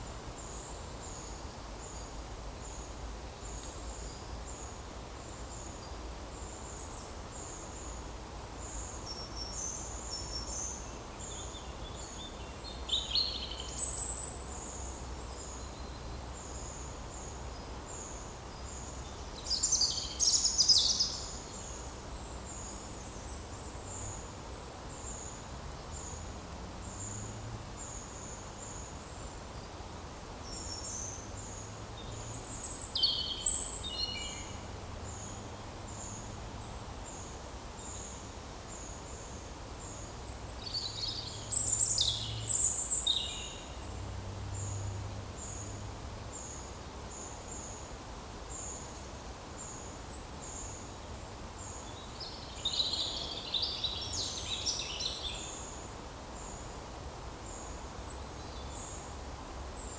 Monitor PAM
Turdus iliacus
Certhia familiaris
Erithacus rubecula
Certhia brachydactyla
Regulus ignicapilla
Aegithalos caudatus